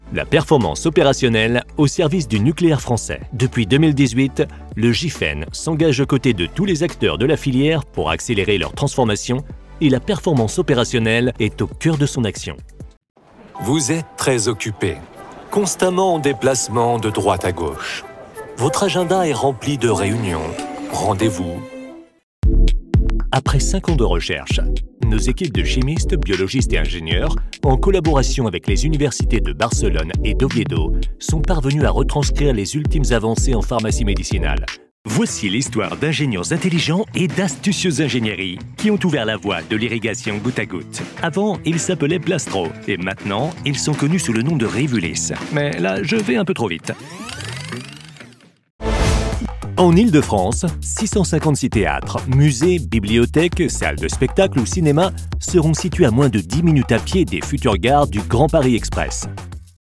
I have a unique voice, soft, friendly, warm, nice and unforgettable.
Versatile and charismatic voice.
1106corporate_demo.mp3